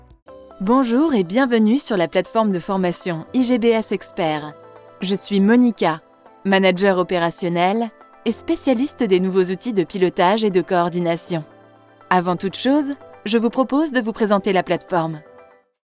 Joven, Natural, Amable, Empresarial
E-learning